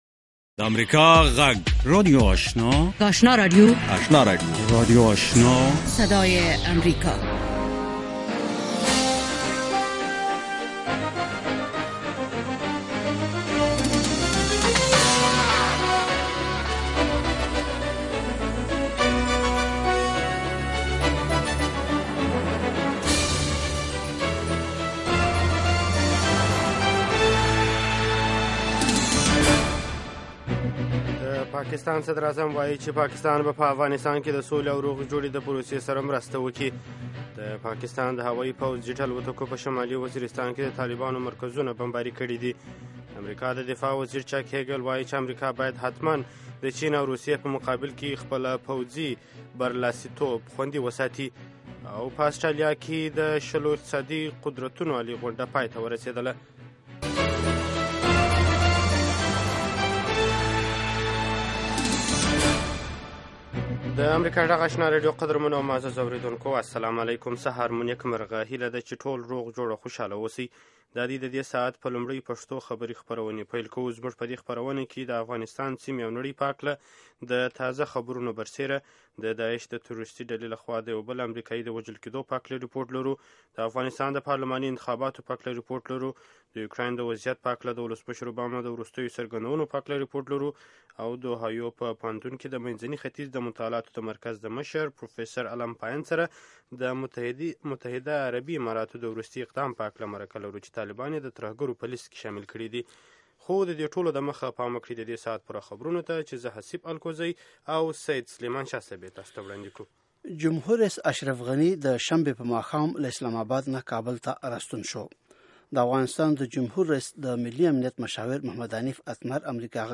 لومړنۍ سهارنۍ خبري خپرونه